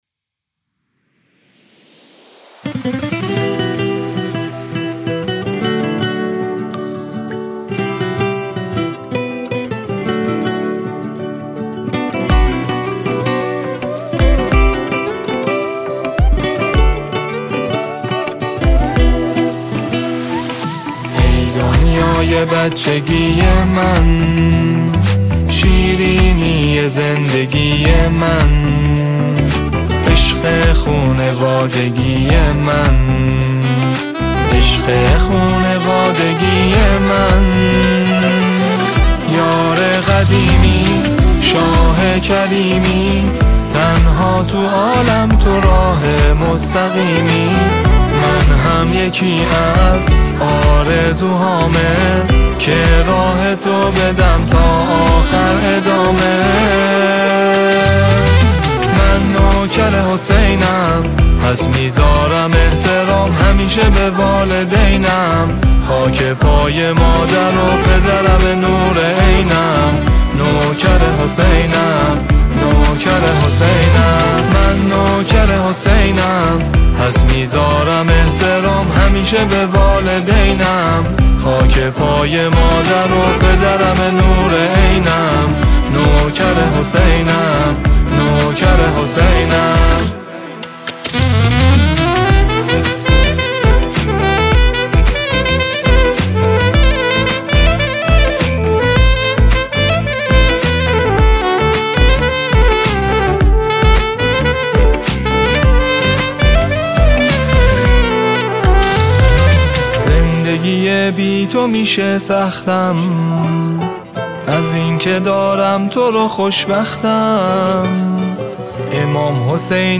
سرود ، سرود مذهبی ، سرود مناسبتی